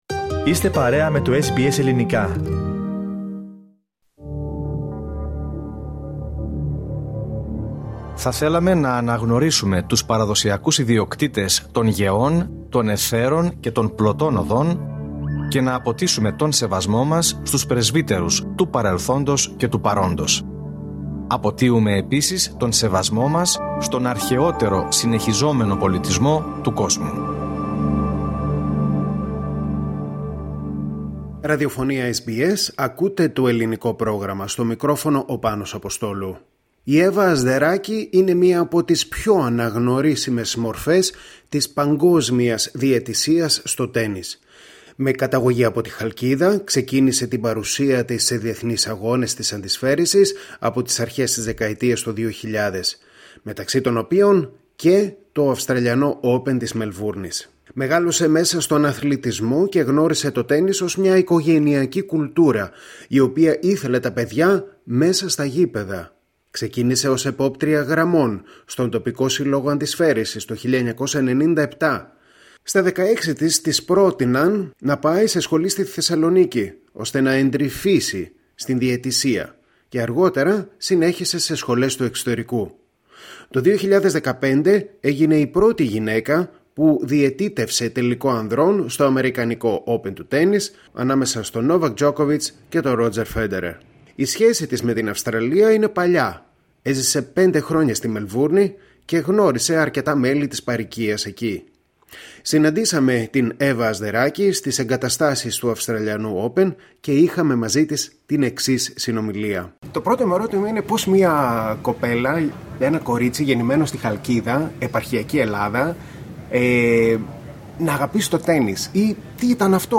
Η διαδρομή της Εύας Ασδεράκη, μιας από τις κορυφαίες διαιτήτριες αντισφαίρισης παγκοσμίως, σε συνέντευξη που παραχώρησε στο SBS Greek